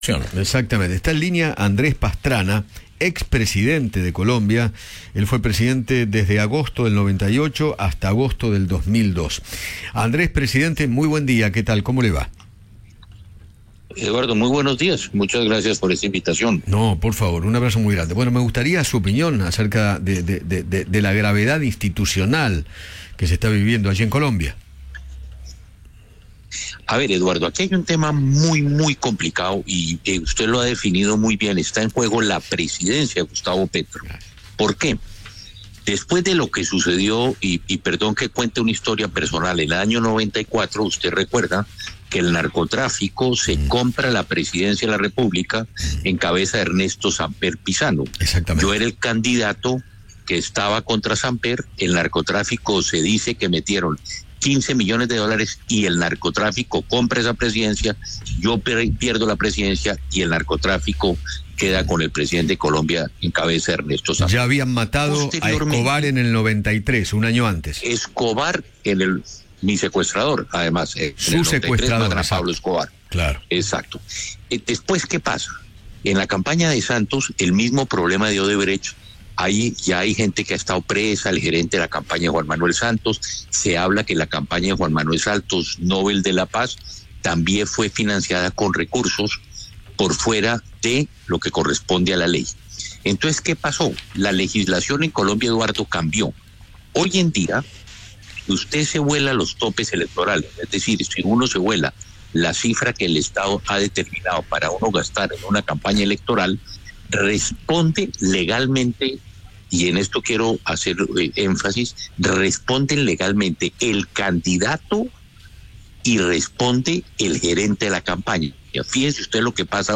Andrés Pastrana, ex presidente de Colombia, habló con Eduardo Feinmann sobre la situación política de su país tras la acusación contra el hijo de Gustavo Petro por lavado de activos.